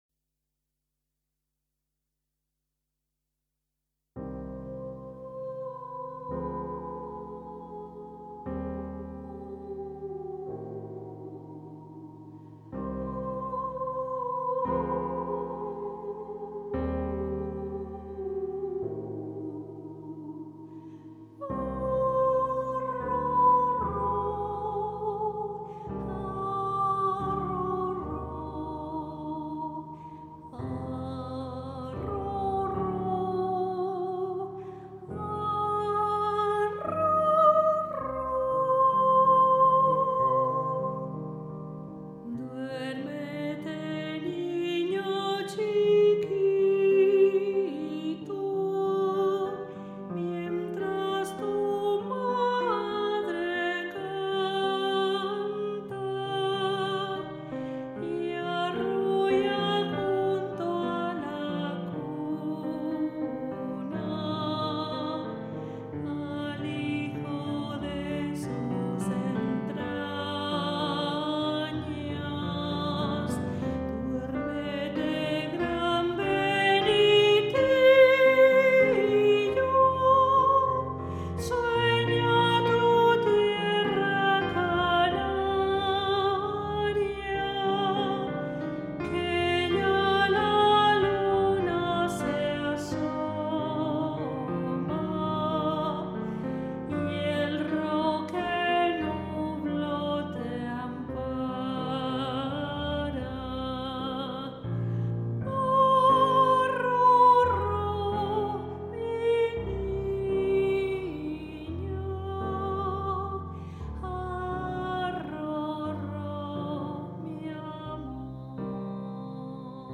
arroro.mp3